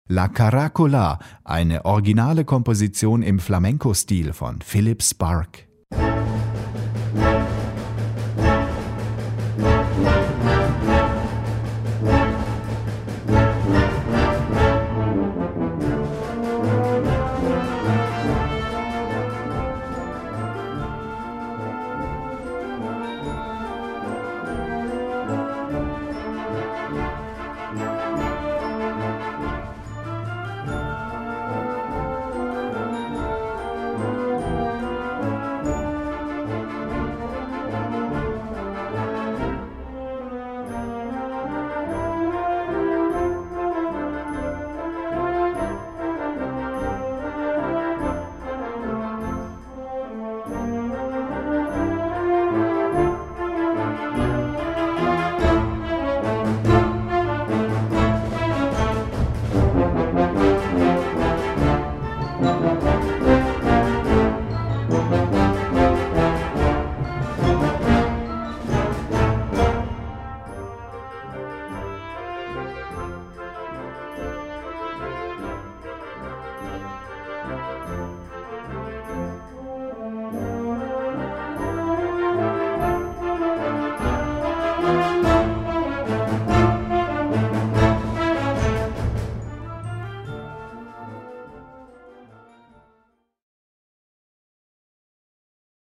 Gattung: Originale Unterhaltunsmusik
Besetzung: Blasorchester